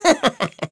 Neraxis-Vox_Happy1.wav